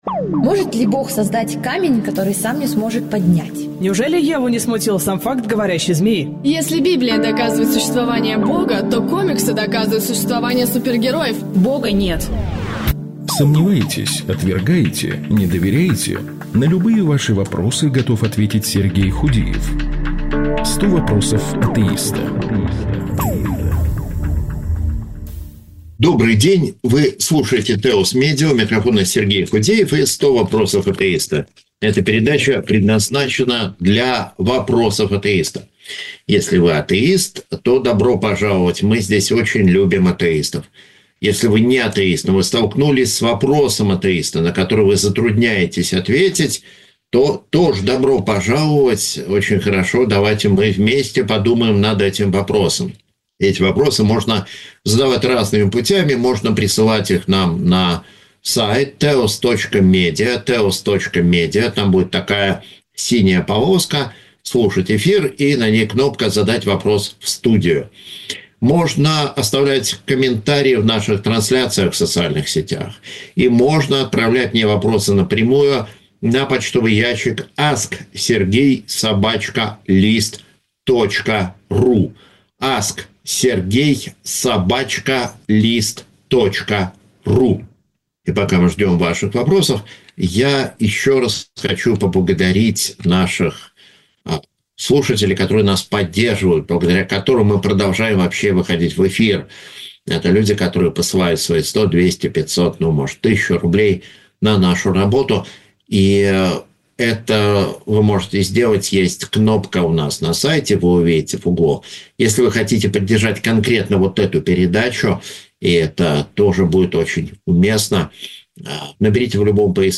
В прямом эфире